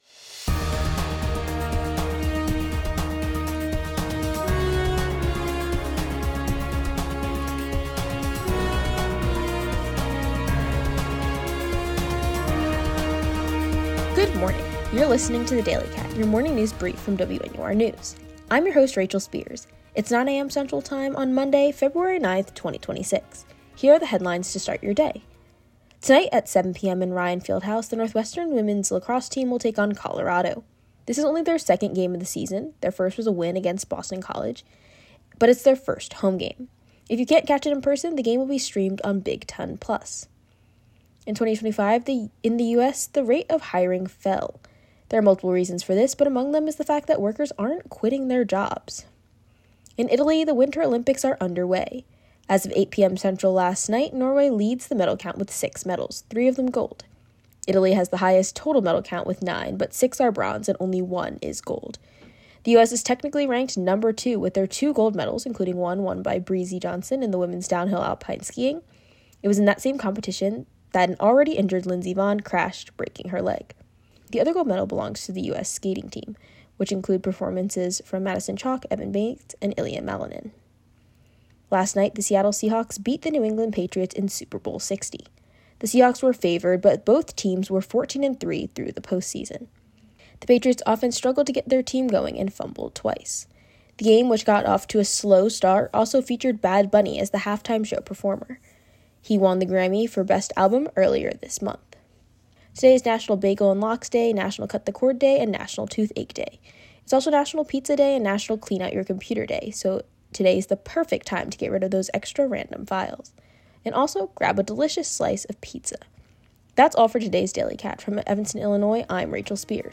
Monday February 9, 2026: Northwestern Lacrosse, Winter Olympics, Super Bowl 60. WNUR News broadcasts live at 6 pm CST on Mondays, Wednesdays, and Fridays on WNUR 89.3 FM.